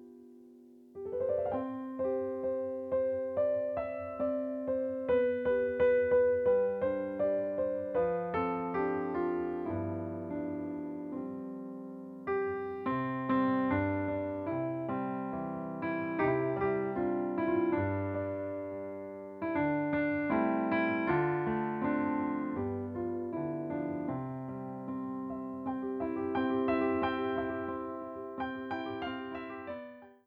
Popular Jewish Music
This piano selection